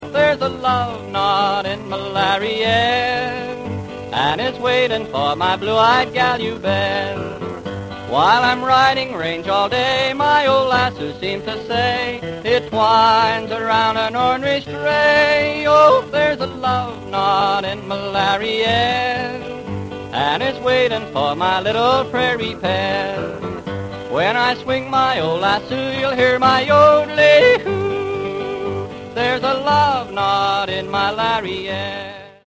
suggesting high spirits and exuberance